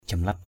/ʥʌm-lap/ (t.) xỏ lá, xấc láo, vô lễ.